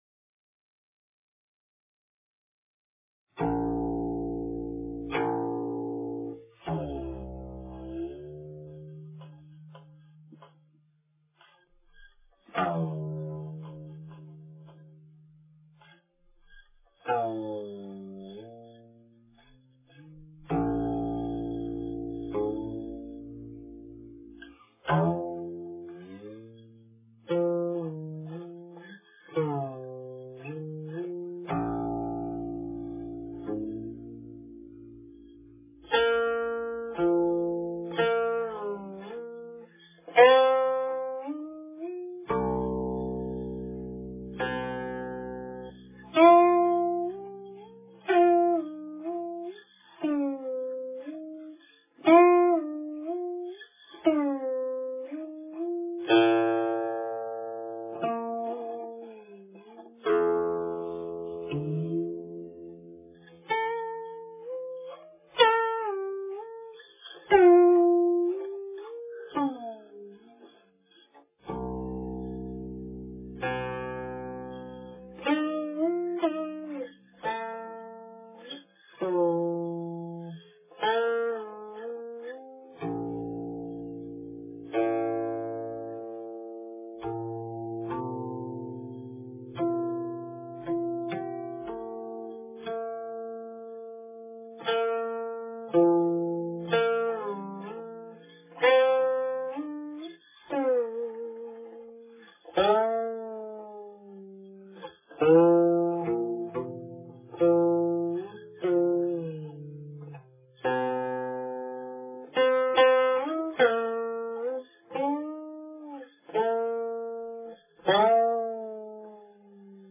佛音 冥想 佛教音乐 返回列表 上一篇： 一曲云水释禅心--笔尖新 下一篇： 心灵之翼--李志辉 相关文章 晨钟偈